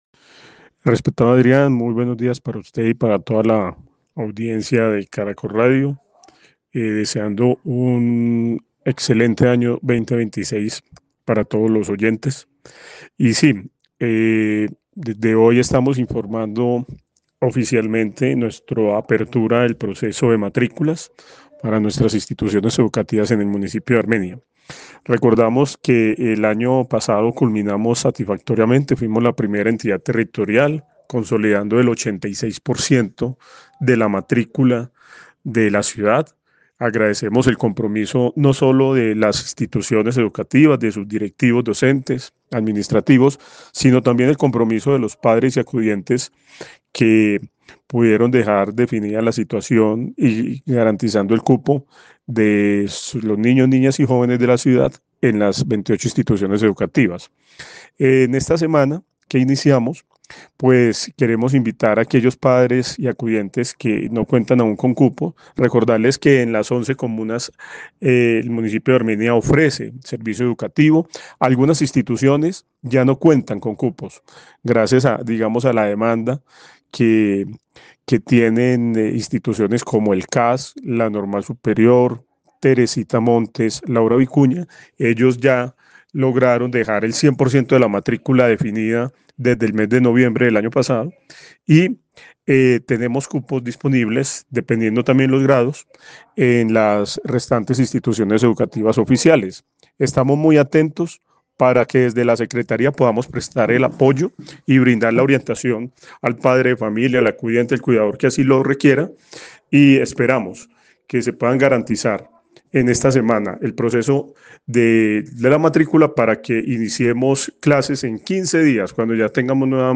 Antonio Vélez secretario de educación de Armenia